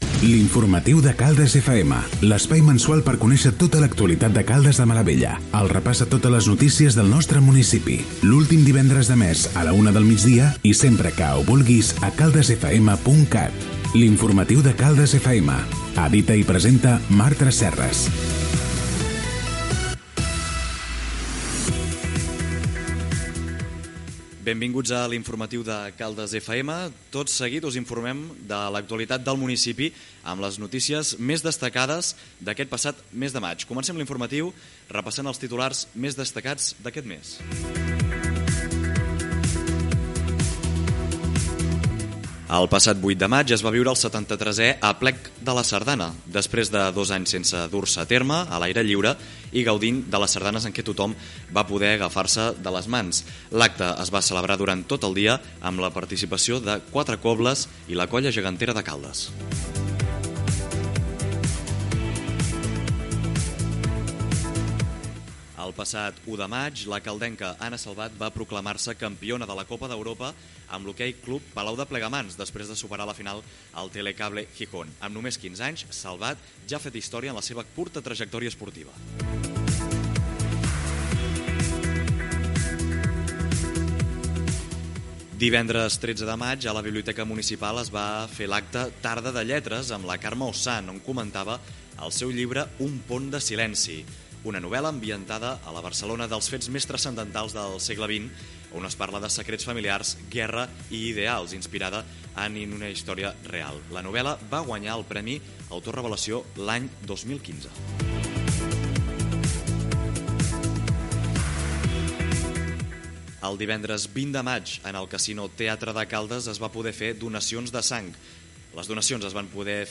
Careta, benvinguda, titulars de les notícies del mes de maig al municipi.
Informatiu